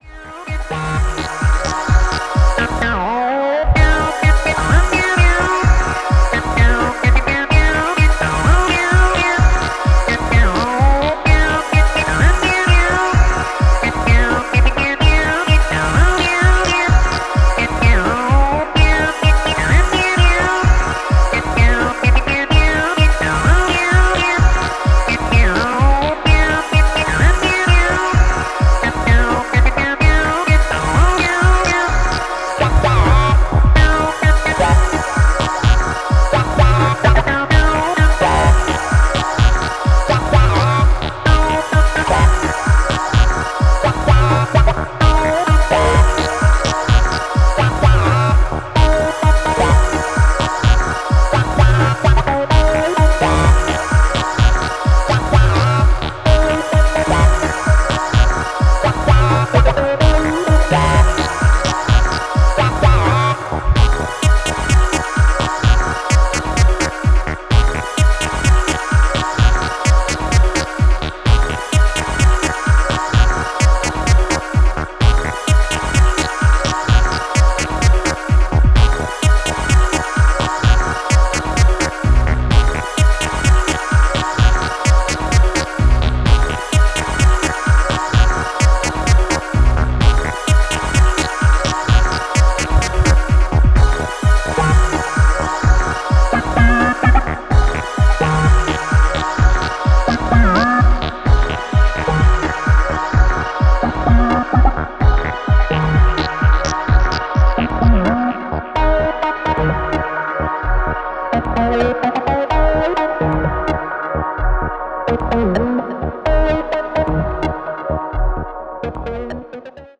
Electro House